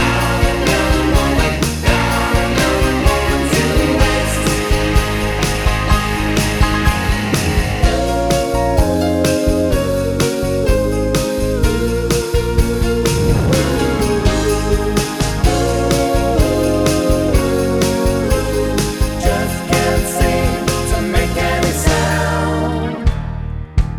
No Intro One Semitone Down Rock 4:21 Buy £1.50